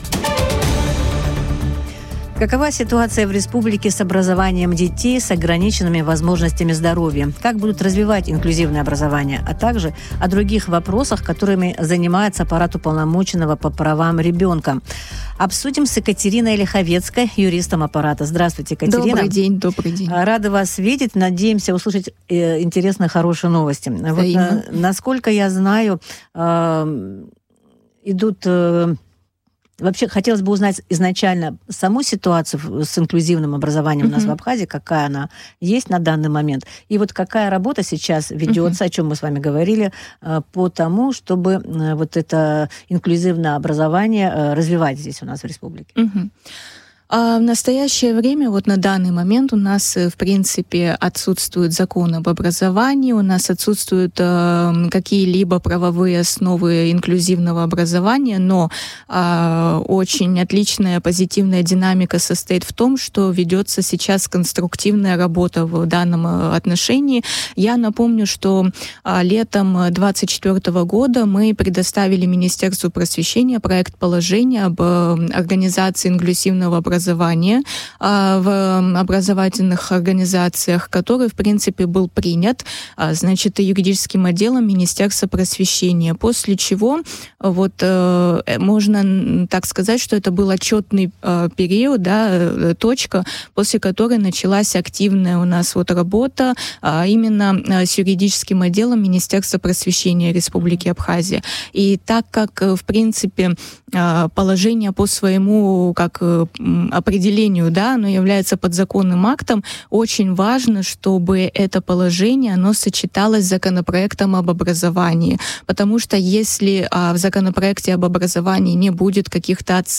в эфире радио Sputnik рассказала о доступности образования для детей с ограниченными физическими возможностями.